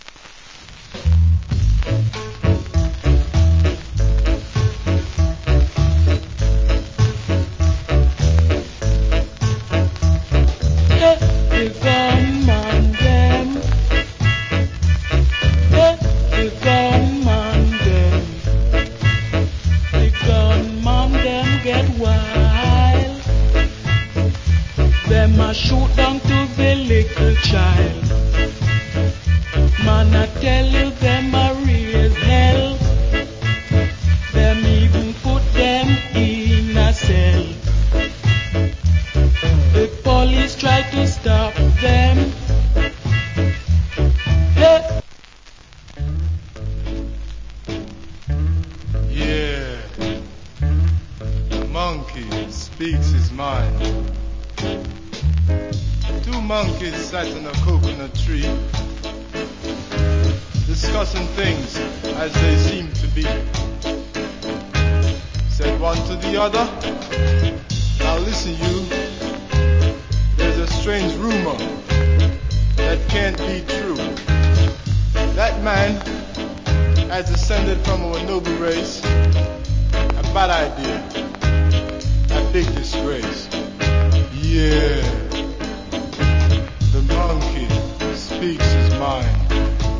Rude Boy Rock Steady.